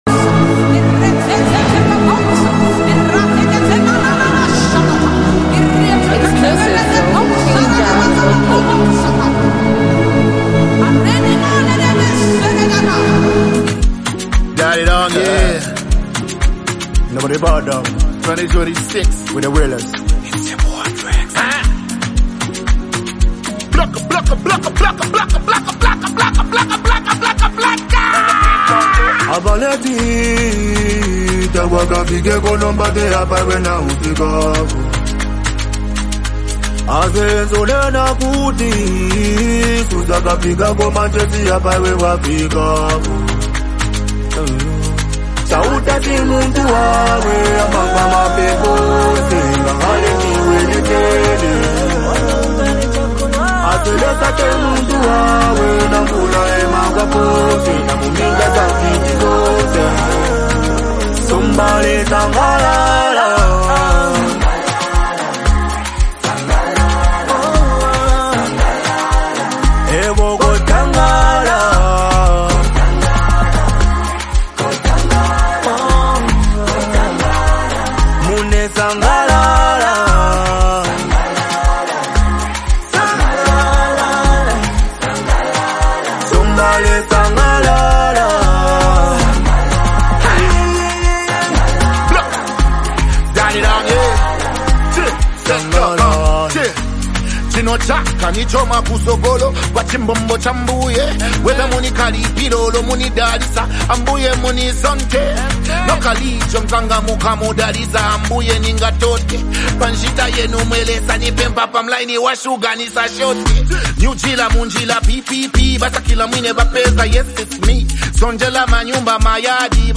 rap sensation